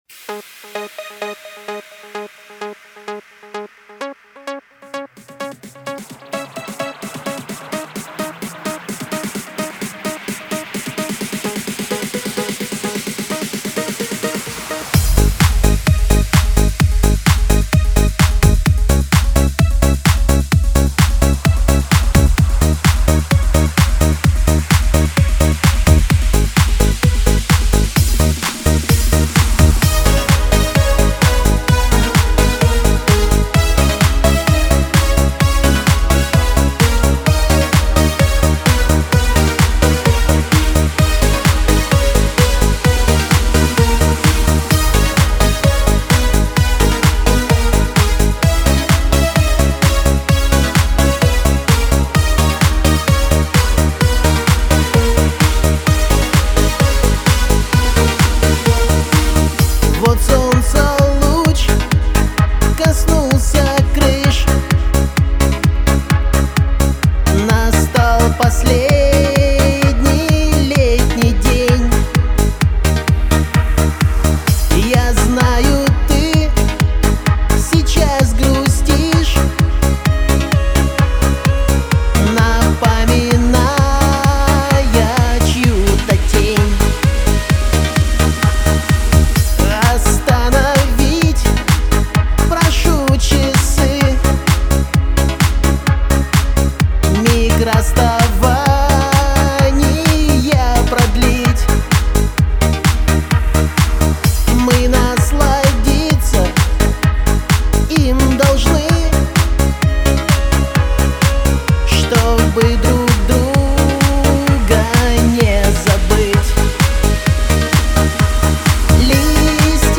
Всі мінусовки жанру Disco
Плюсовий запис